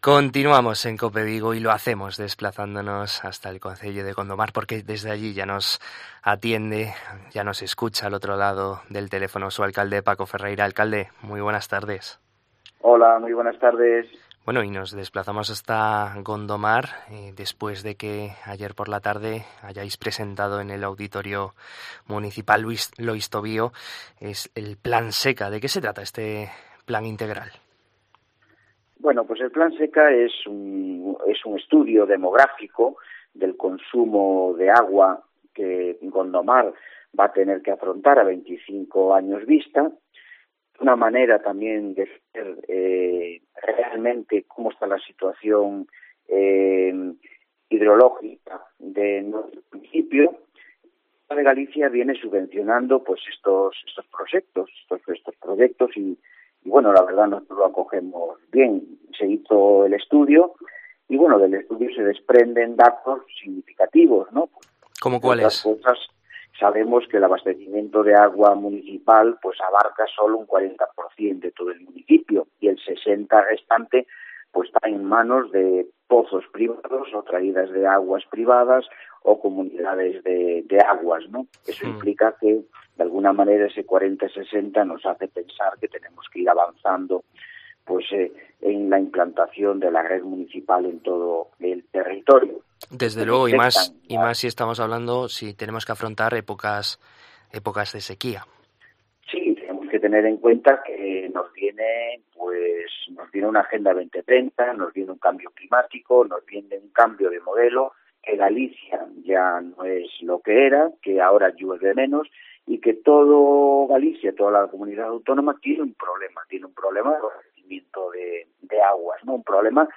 En COPE Vigo hablamos con el alcalde de Gondomar, Paco Ferreira, para conocer la actualidad de este municipio del sur de la provincia de Pontevedra